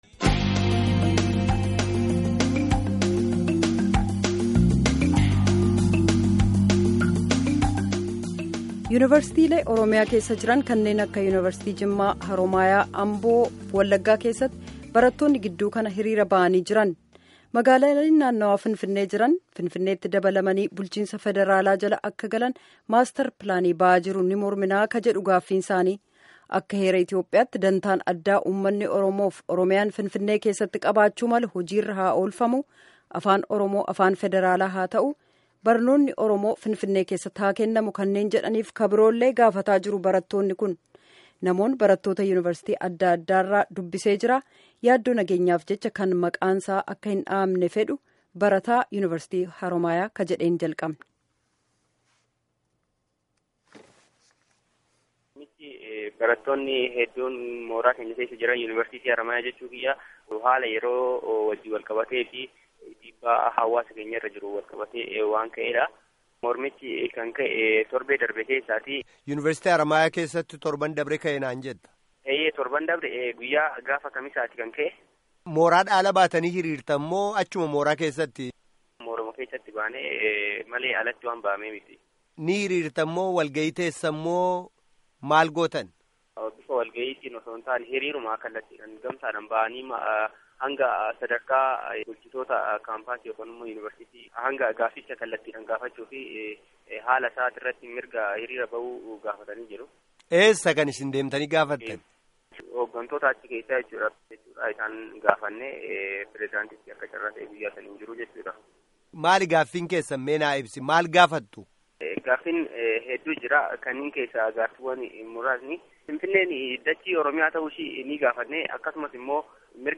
Gaaffii fi Deebii gaggeeffame kutaa 2ffaa caqasaa